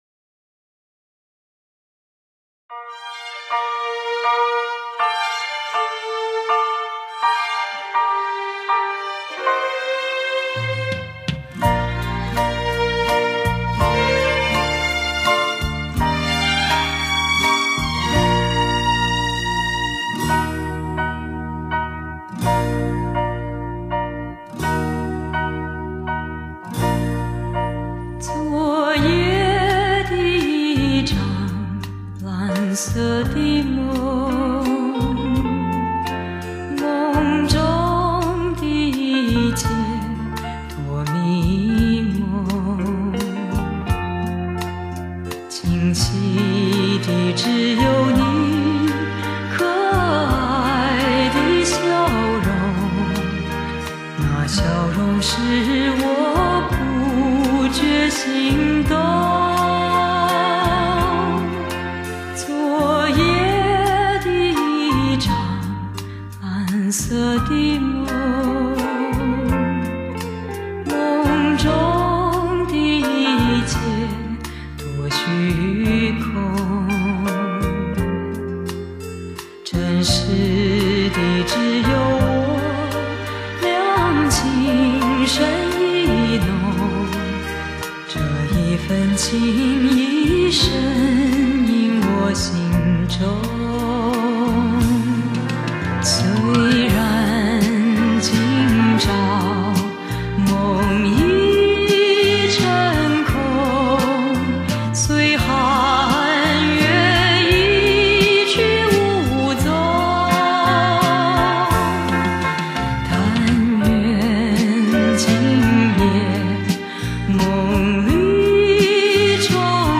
用心去品味这优雅宁静的歌声，感受最深情的吟唱。
i like her voice, soothing when you are really down.